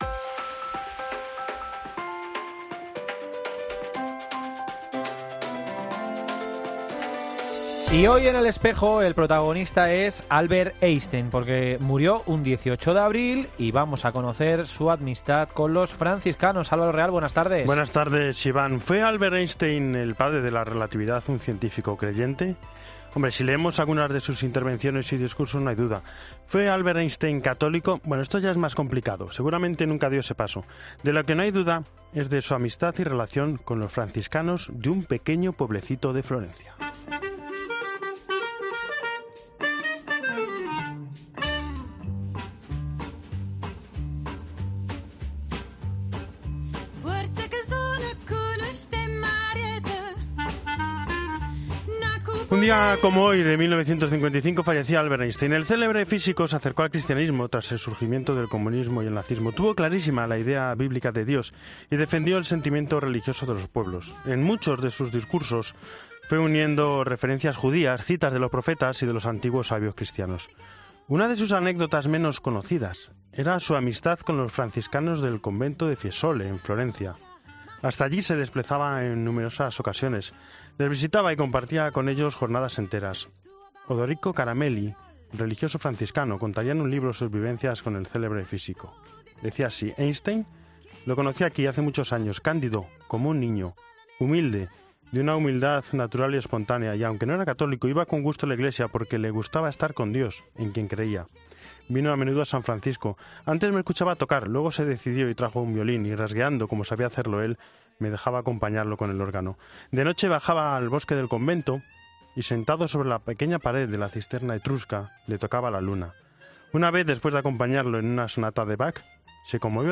En Espejo 18 abril 2021: Entrevista a Monseñor Elizalde sobre Congreso Integracion en tiempos de pandemia